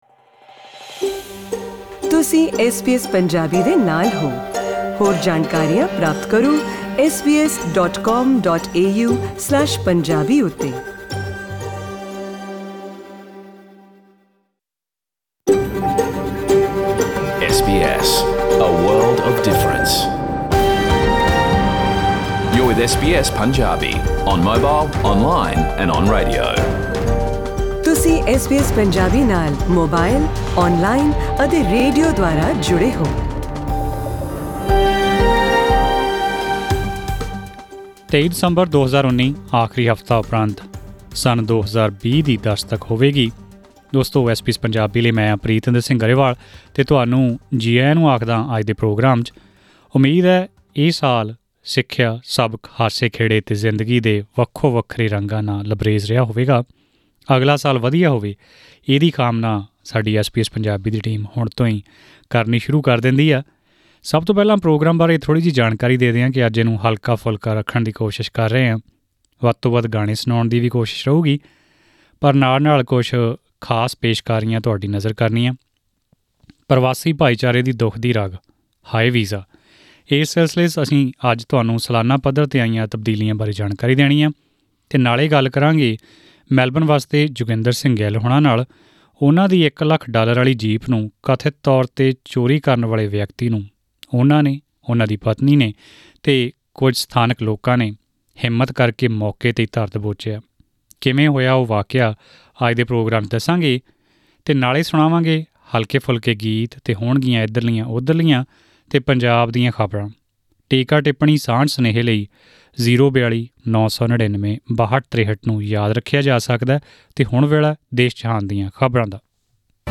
In today’s news bulletin - Scott Morrison defends the government's bushfire response. Tributes for former Victorian premier John Cain who's died aged 88. and in cricket, India beat West Indies to clinch ODI series.